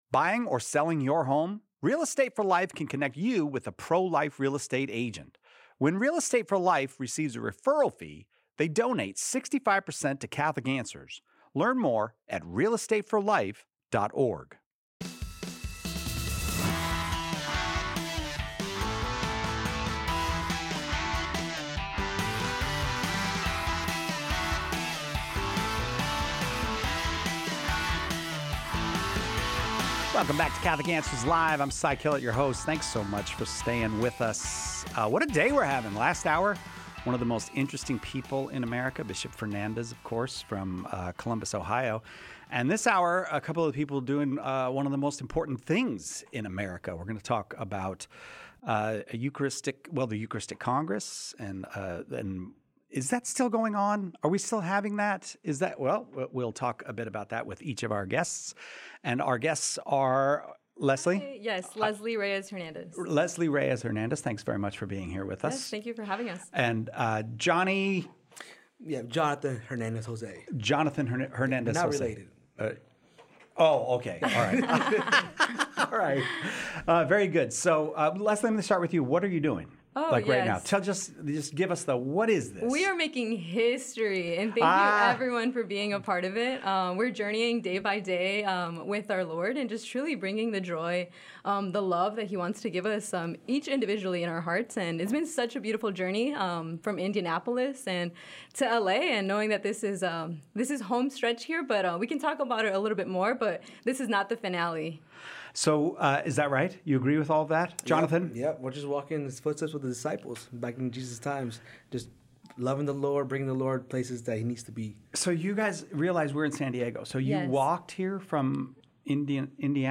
Young Catholics share their experiences from the National Eucharistic Pilgrimage—stories of healing, joy, and faith as they bring Jesus across America.